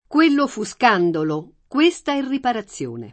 kU%llo fu Sk#ndolo, kU%Sta H rriparaZZL1ne] (Manzoni)